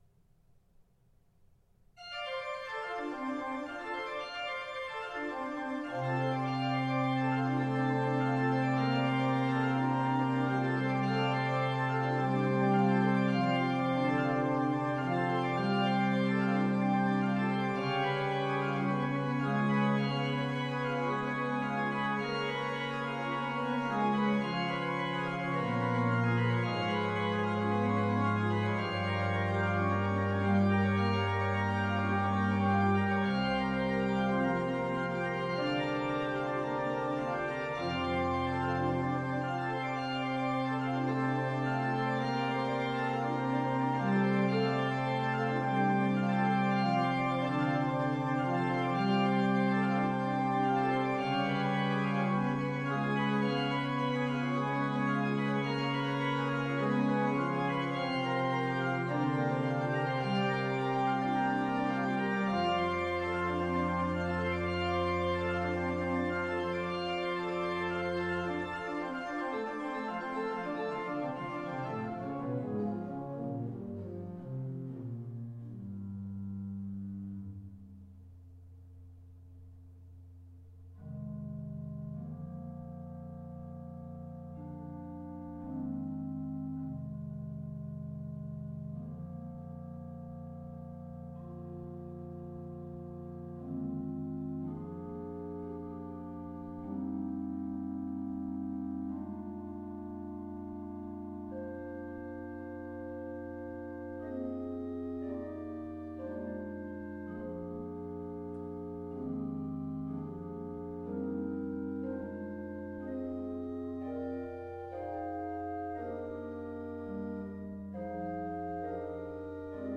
Studierende der Fachbereiche Kirchenmusik und Komposition der Musikhochschulen Frankfurt und Mainz haben kurze Orgelstücke komponiert, die am 12. September 2021 in vielen Kirchen in Hessen und Rheinland-Pfalz uraufgeführt wurden.
Die Kompositionen sind freie, nicht choralgebundene Stücke, mit einer Spieldauer von 3-4 Minuten, auf einer einmanualigen Orgel mit Pedal darstellbar und vom Schwierigkeitsgrad her auch für nebenberufliche Organist*innen mit C- oder D-Prüfung spielbar.